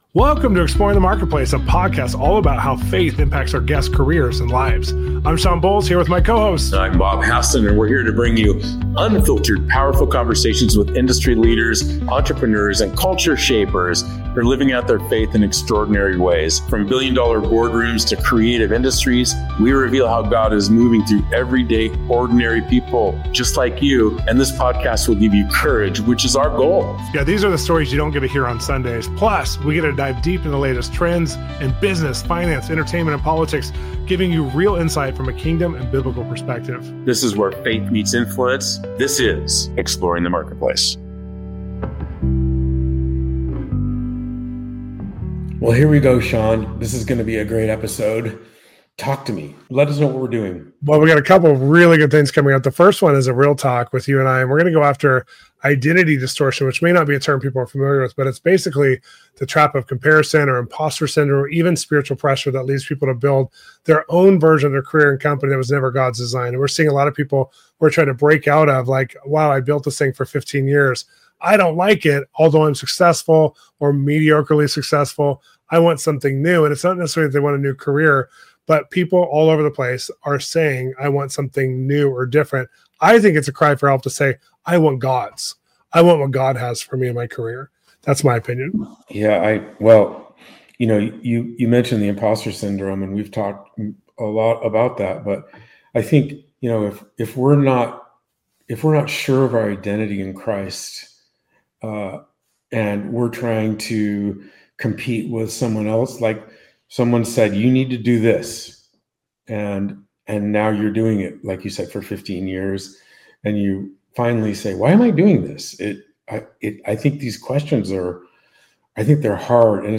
This conversation will challenge, encourage, and equip you to see culture—and your faith—through a sharper, biblical lens.